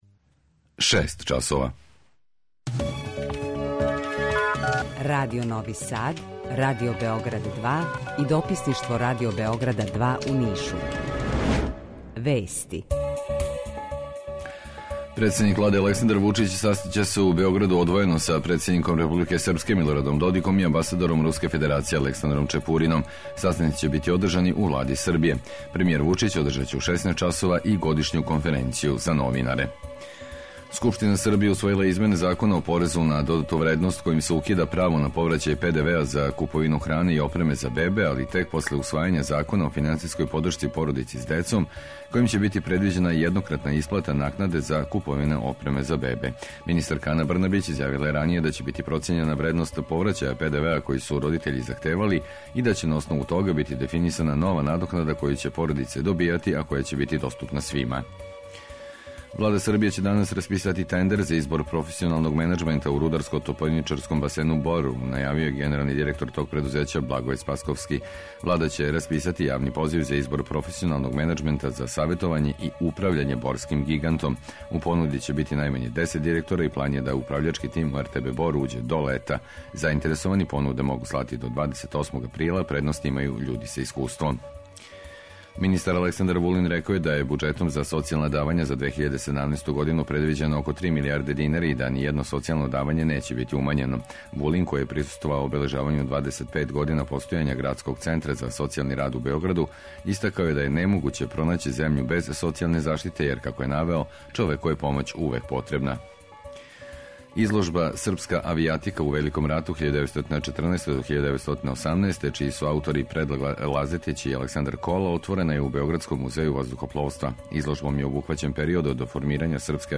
Први пут заједно, у једној емисији из три различита студија, градоначелници Београда, Новог Сада и Ниша - Синиша Мали, Милош Вучевић и Дарко Булатовић, разговараће о актуелним темама које су обележиле годину која је иза нас и плановима за годину која долази.